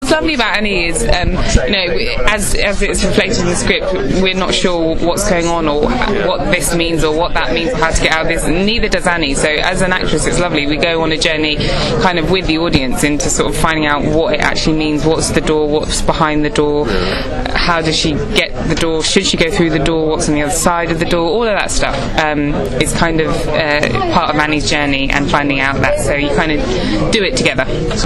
And here’s are a few short audio extracts from the interview with Lenora: